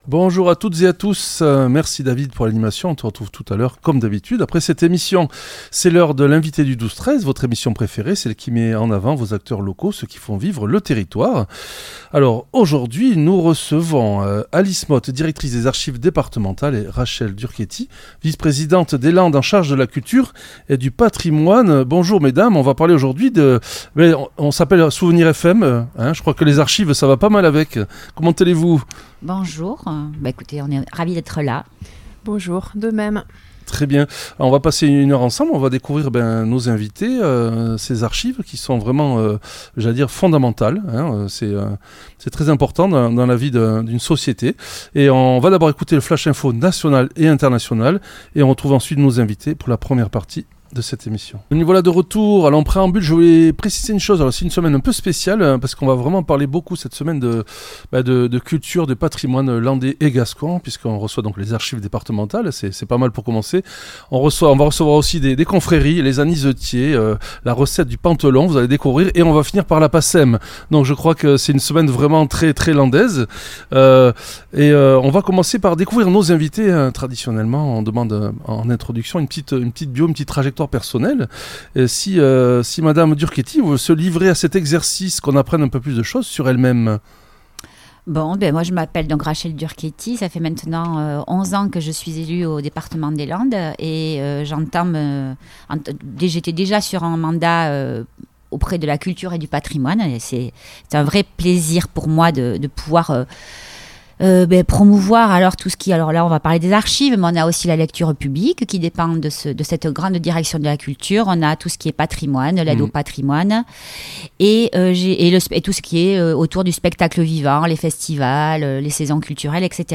L'entretien a mis en lumière l'accessibilité de ces trésors : que ce soit pour de la généalogie, des recherches administratives ou via les ressources numériques (cadastre napoléonien, registres militaires), les Archives sont une mine d'or ouverte à tous.